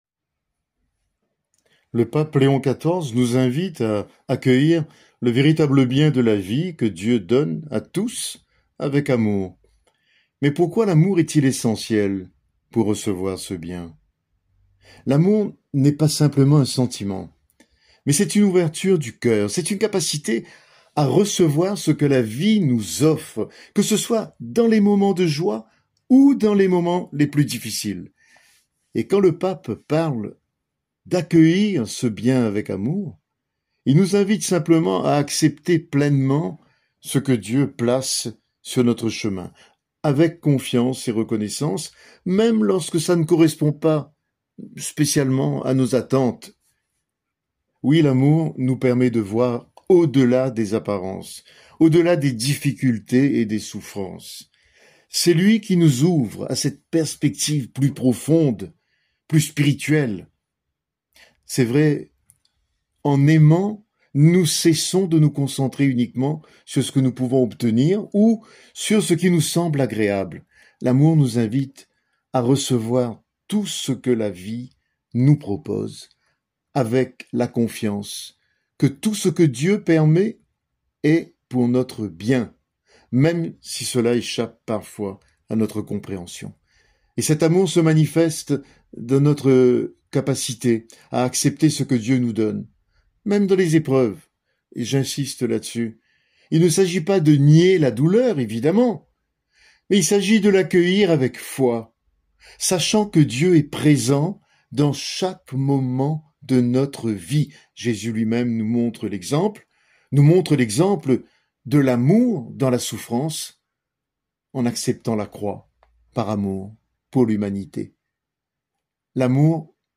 Prédication disponible en format audio.